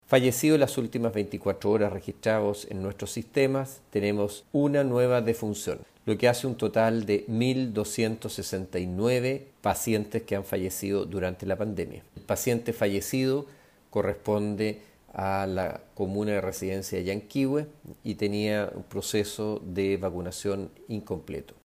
Alejandro Caroca se refirió también a los fallecidos en la región: